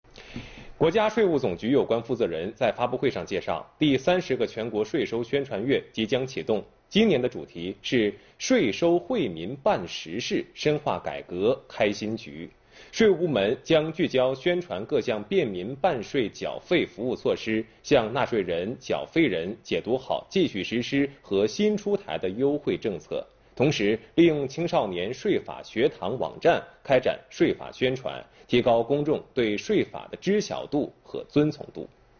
3月29日上午，国务院新闻办公室举行新闻发布会，国家税务总局纳税服务司司长韩国荣在发布会上介绍了今年税收宣传月有关情况，并以此拉开第30个全国税收宣传月序幕。